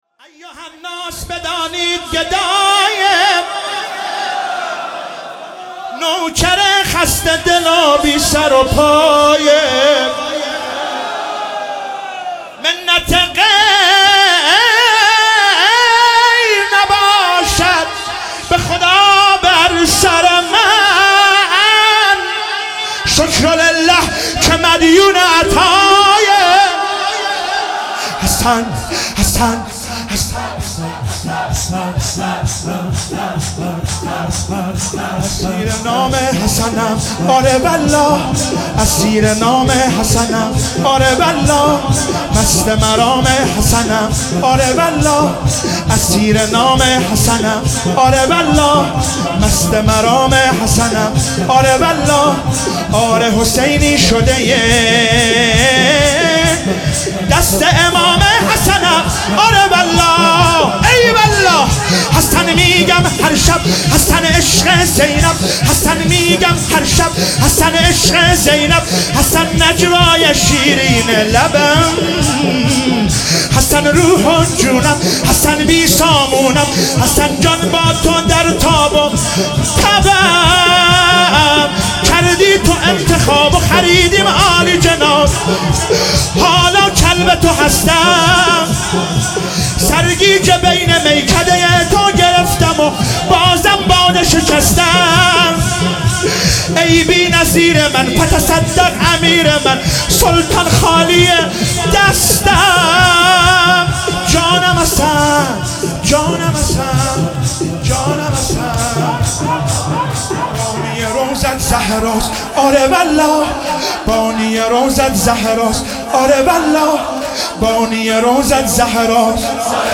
شب سوم محرم96 - شور - ایها الناس بدانید گدای حسنم